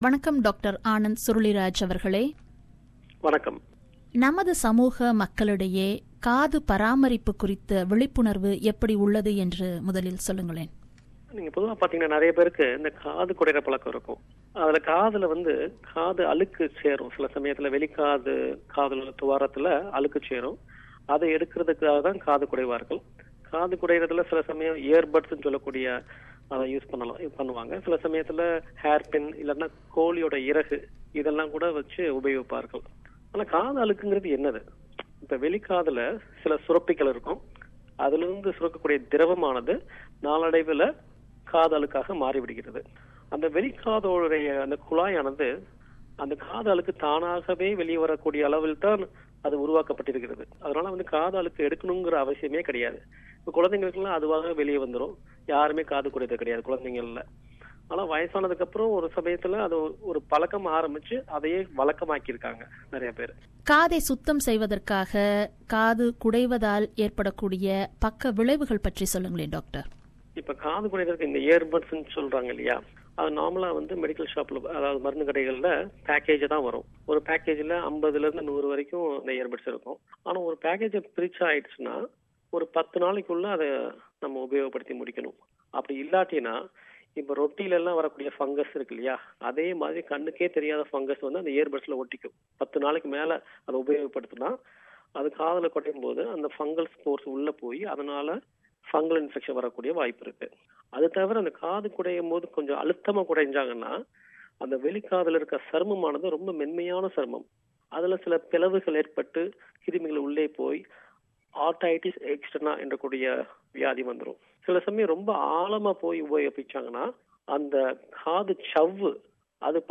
interviewd